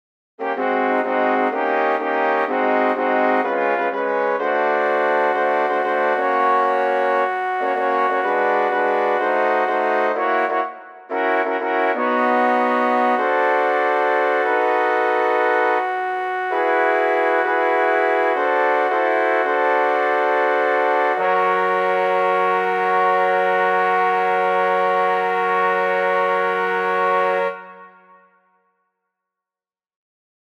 Key written in: F# Major
How many parts: 4
Type: Barbershop
All Parts mix:
Learning tracks sung by